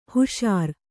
♪ huṣār